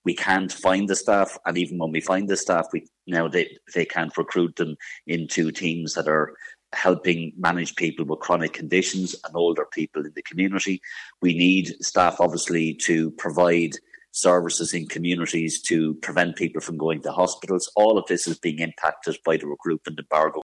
Sinn Fein Health spokesperson David Cullinane says a lack of staff is a core issue: